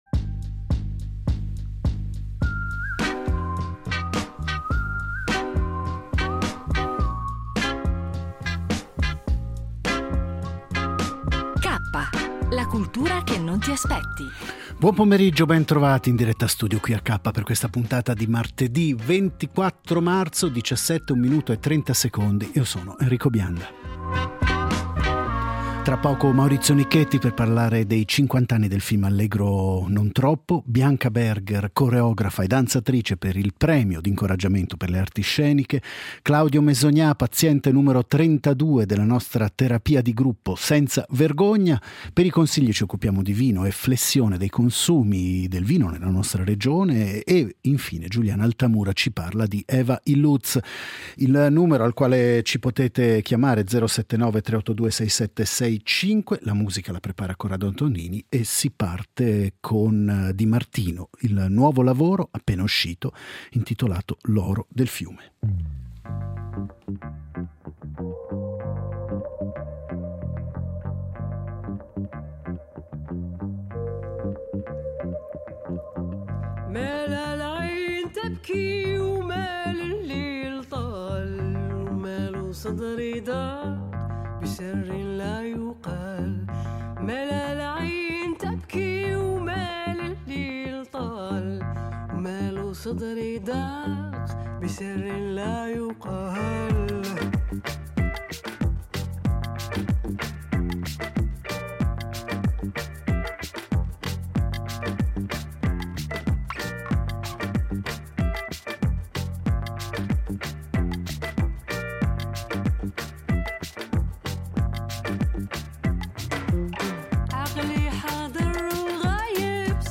Partiamo oggi con la celebrazione del 50° anniversario del film “Allegro non troppo” di Bruno Bozzetto, con un’intervista a Maurizio Nichetti .